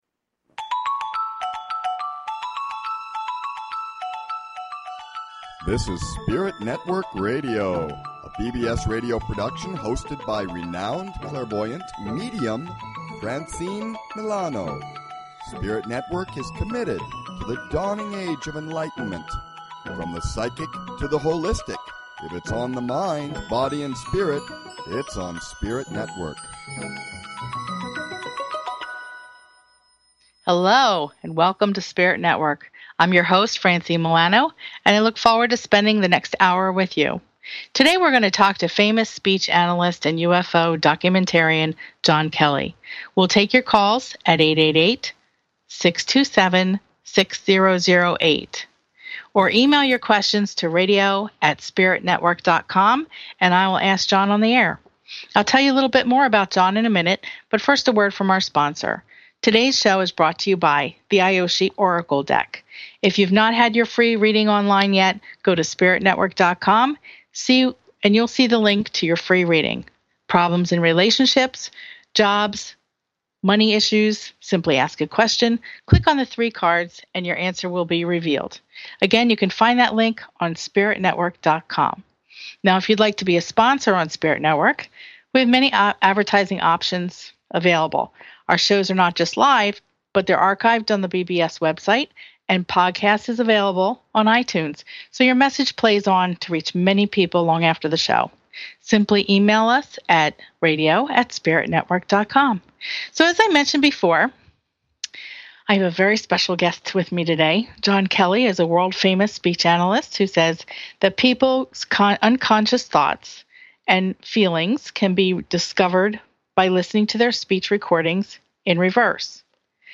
SpiritNetwork is a straight-forward spiritual variety show with something new to look forward to in every show.